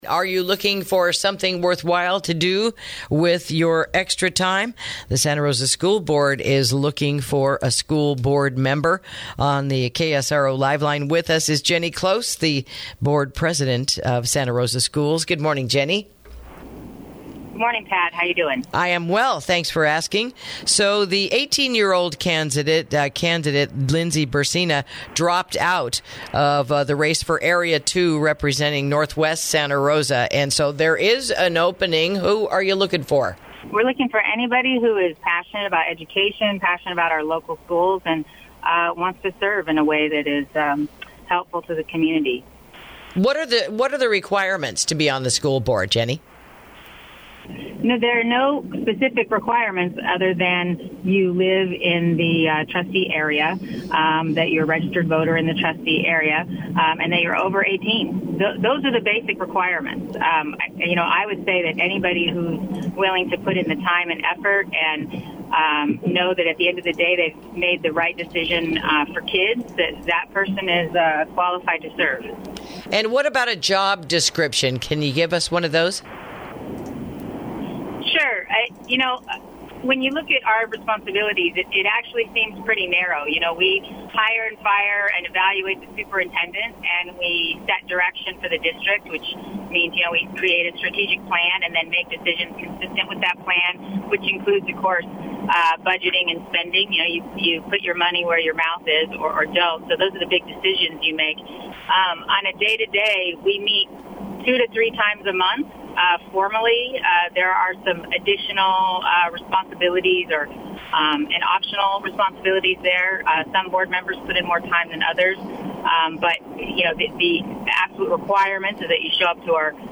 INTERVIEW: The Santa Rosa City School Board is Seeking a New Member to Add to Their Ranks